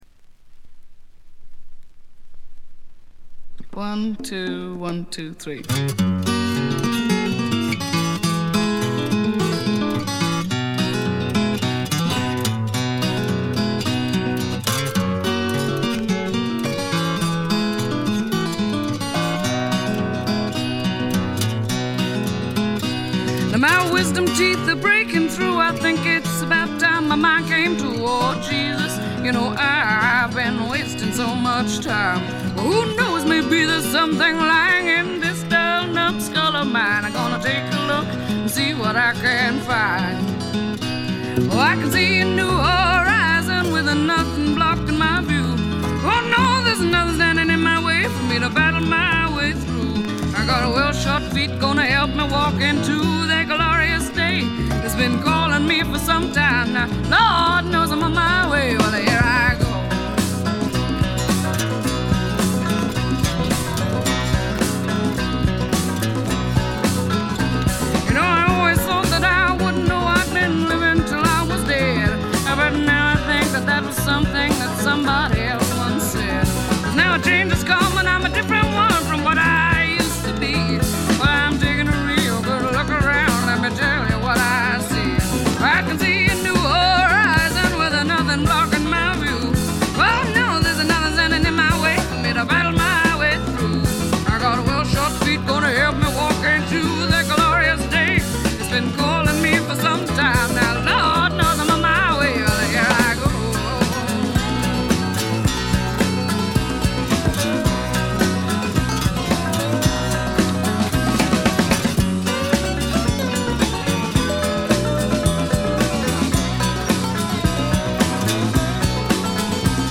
試聴曲は現品からの取り込み音源です。
Recorded at Nova Sound Recording Studios, London